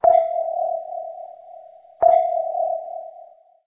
Звуки эхолота
Звук отраженного сигнала эхолота в водоеме